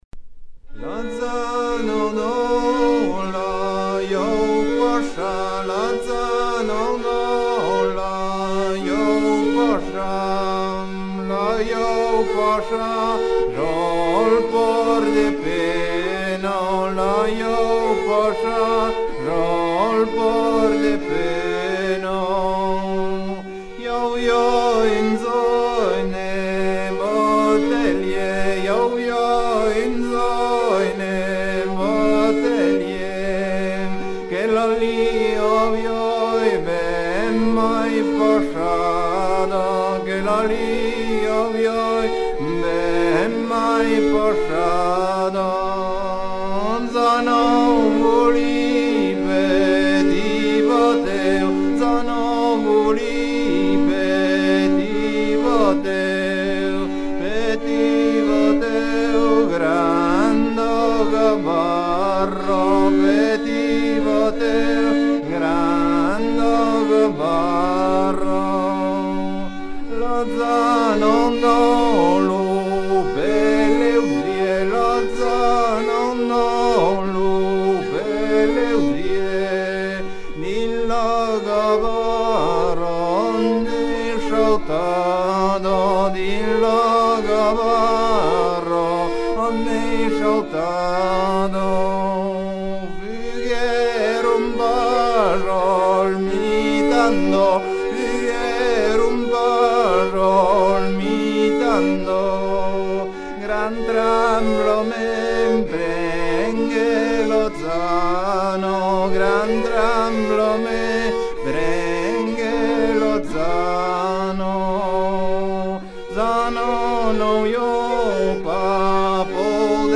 Version recueillie vers 1980
Pièce musicale éditée